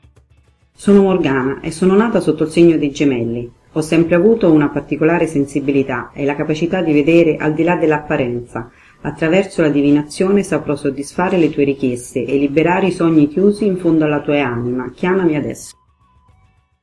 Attraverso la sua voce sentirai fluire la serenità e, attraverso le sue capacità, la speranza tornerà ad abitare il tuo cuore.